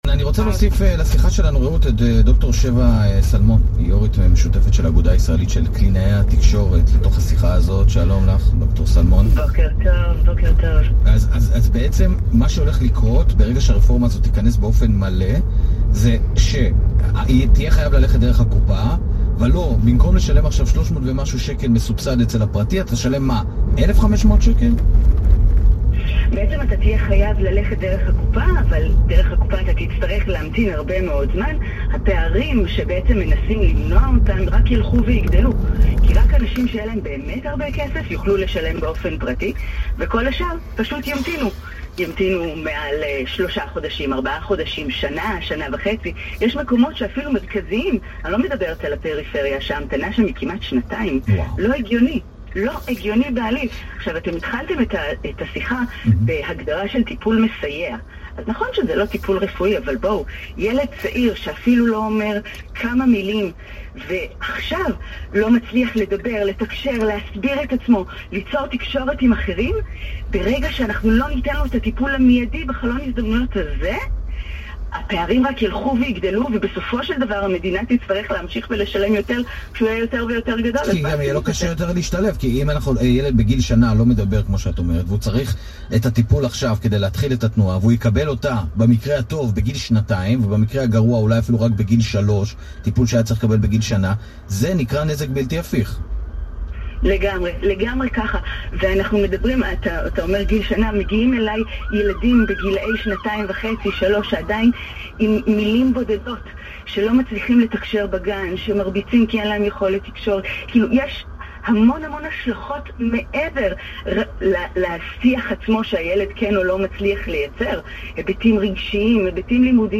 ראיונות ברדיו